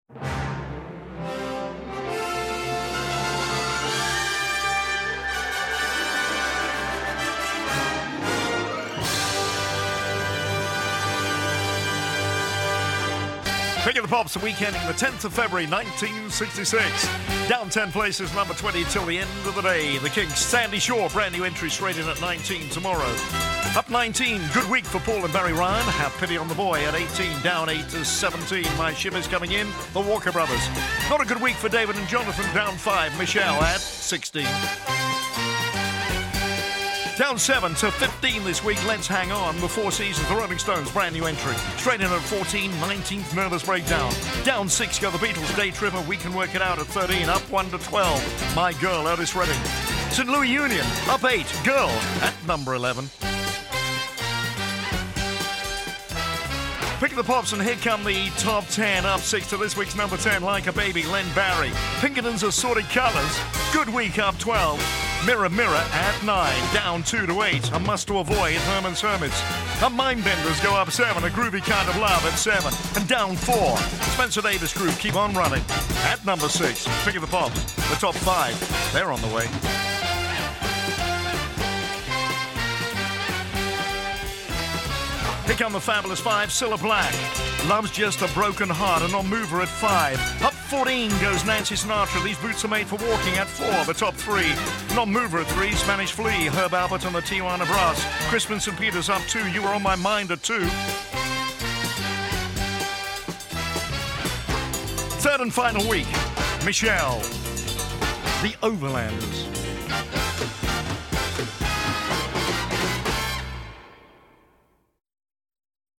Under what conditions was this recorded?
Here's the top 20 UK chart rundown from the week ending 10th February 1966, as broadcast on Pick of the Pops.